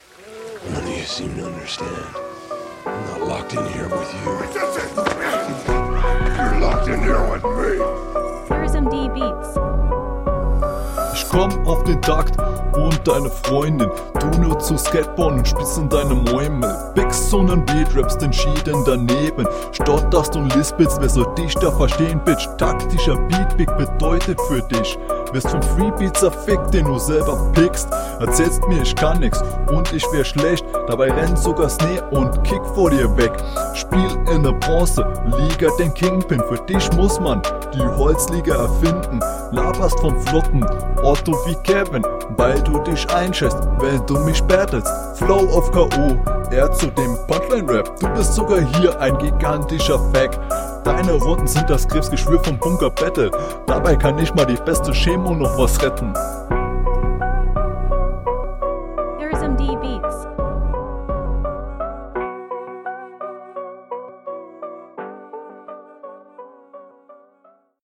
Du kommst besser auf den Beat als dein Gegner.